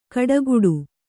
♪ kaḍaguḍu